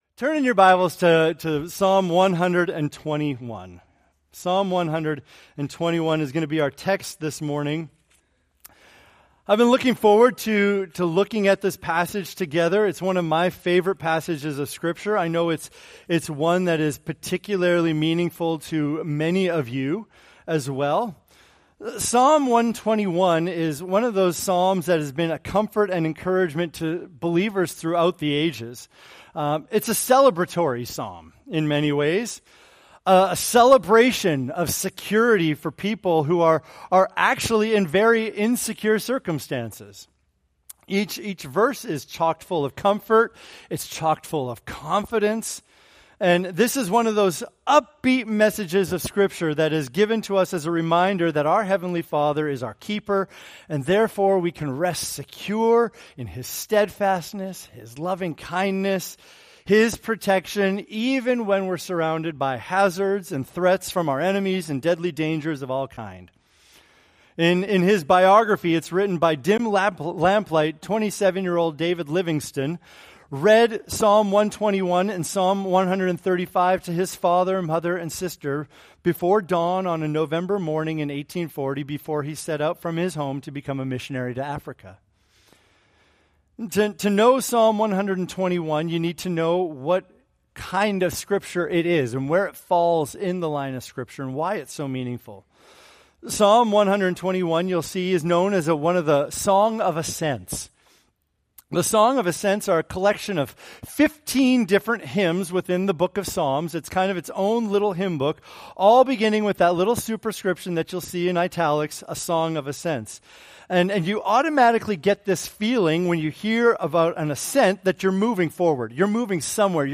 Preached August 18, 2024 from Psalm 121